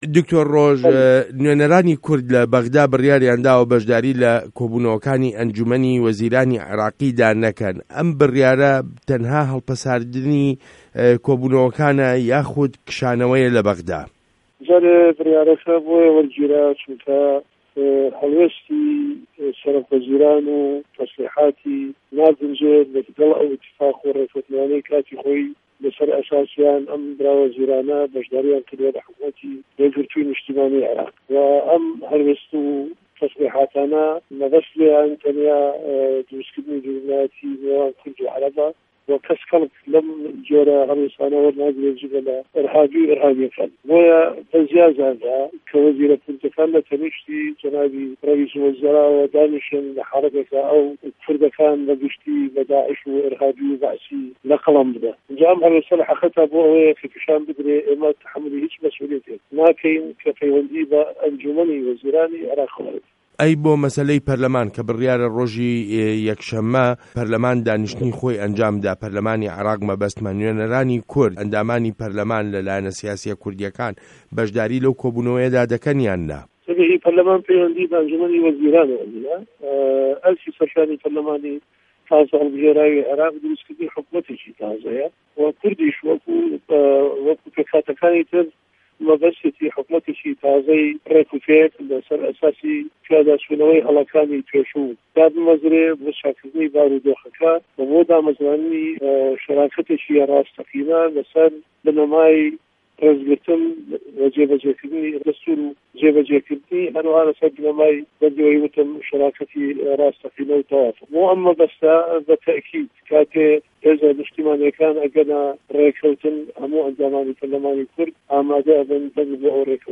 وتووێژ له‌گه‌ڵ دکتۆر ڕۆژ نوری شاوێس